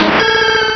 Cri de Méganium dans Pokémon Rubis et Saphir.